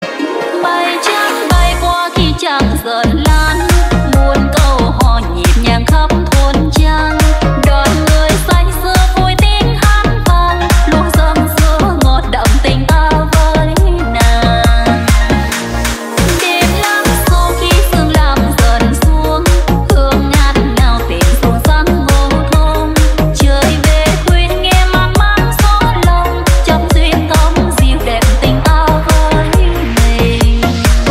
Nhạc Chuông Remix